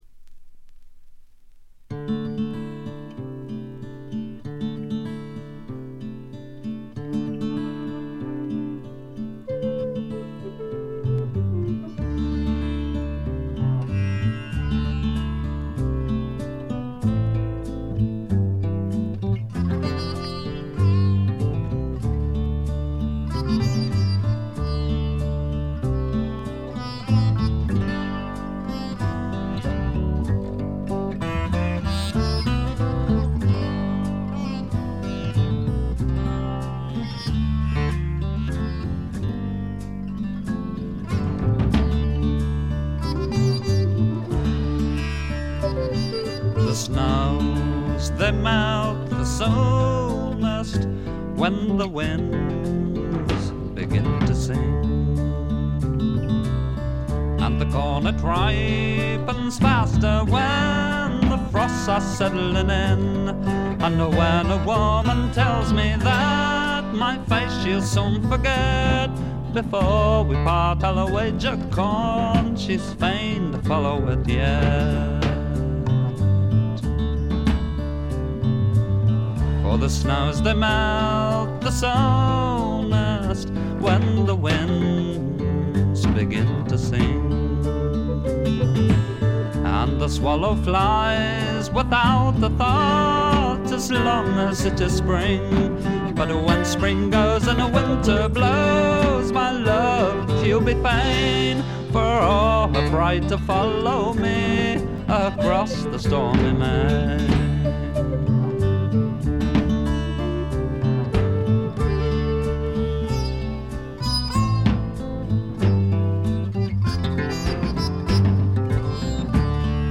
部分試聴ですが軽微なノイズ感のみ。
試聴曲は現品からの取り込み音源です。